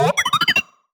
sci-fi_driod_robot_emote_21.wav